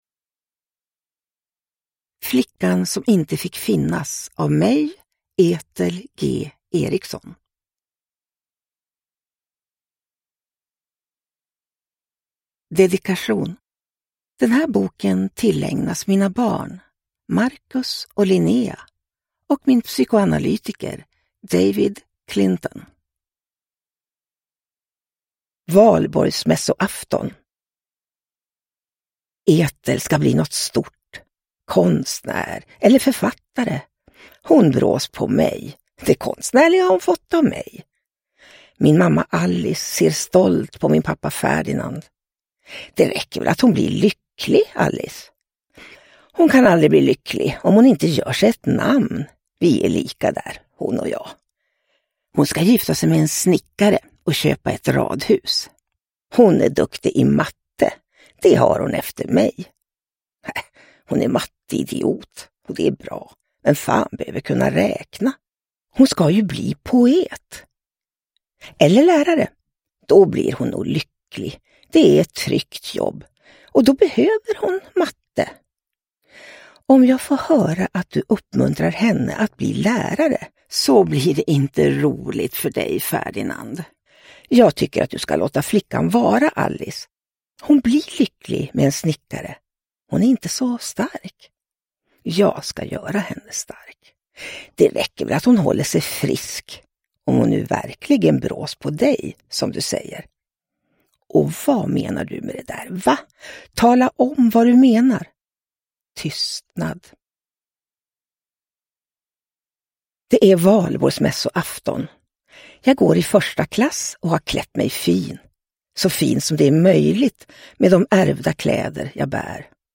Flickan som inte fick finnas – Ljudbok – Laddas ner